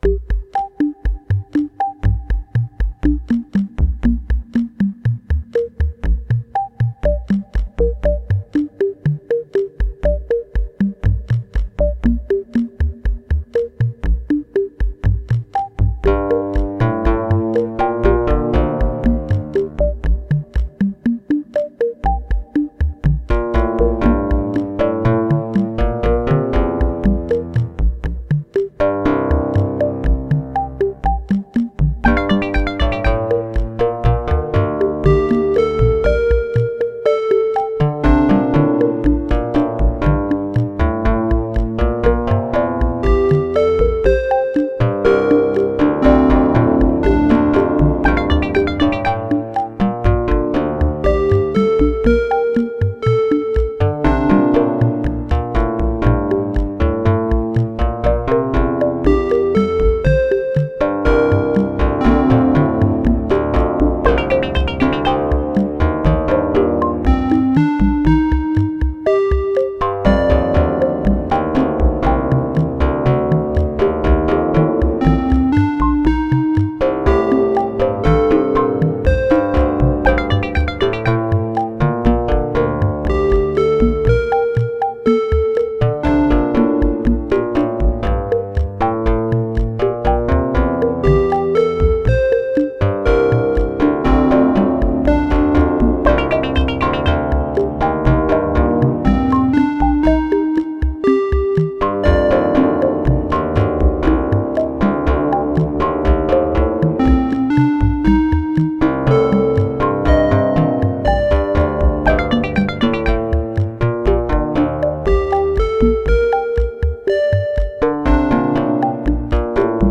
• AE Modular synthesizer rack shown as configured here, notably including three GRAINS modules, two MCO/1 modules, and many others.
The song is obviously too heavy in bass and too muddy.
PLUCK also has difficulty maintaining pitch.